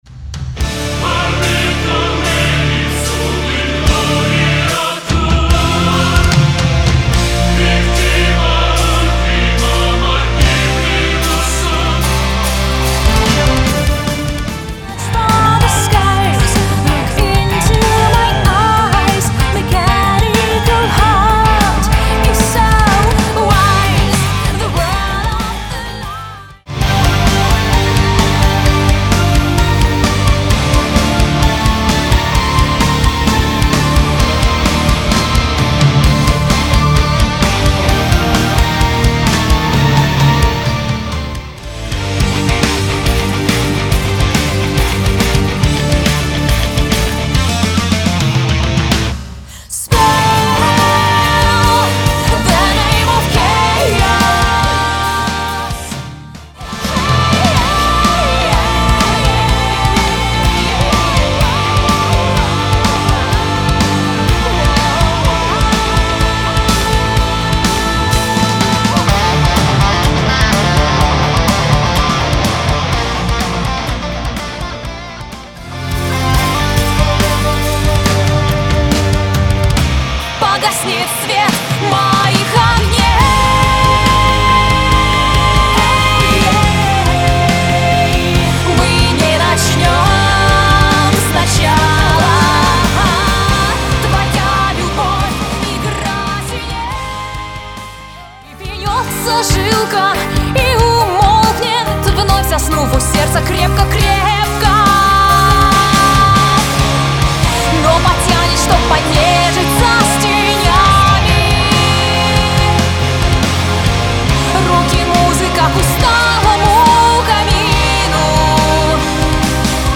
Альбом записан на студии"Восход" (СПб)